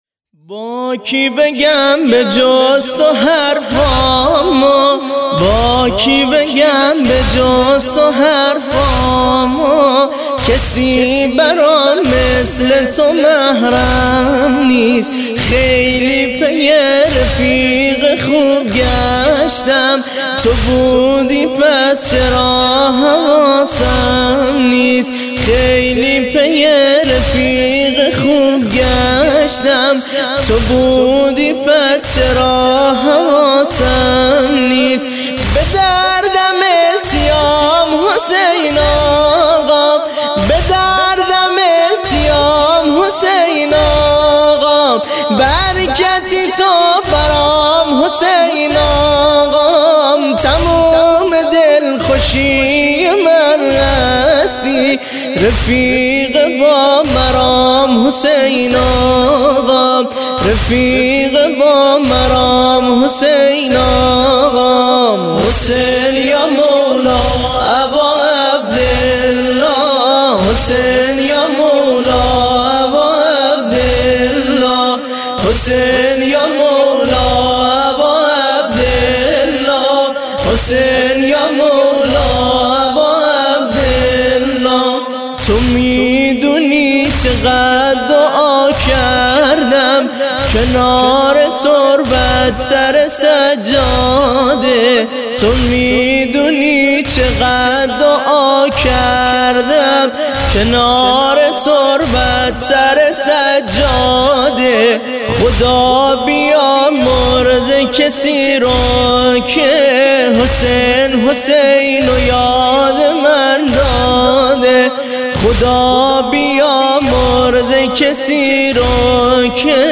نوحه محرم 99